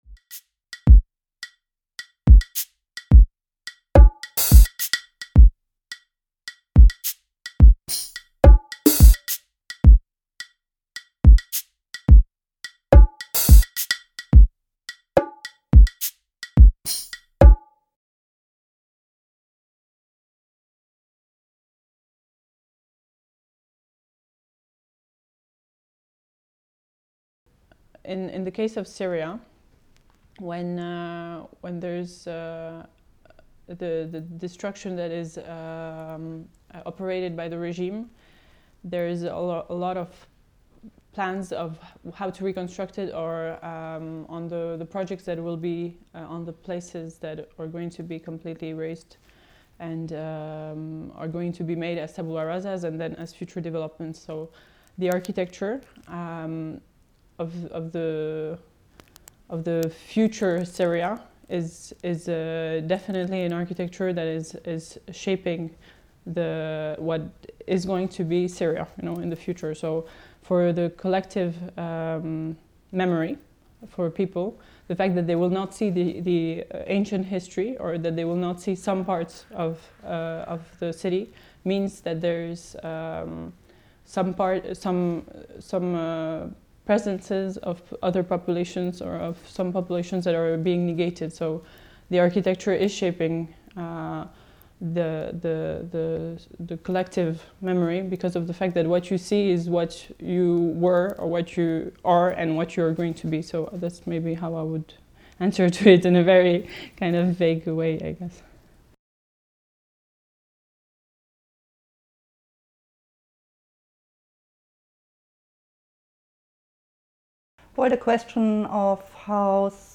Public Memory Study Group 2ndof March 2018 Interview Questions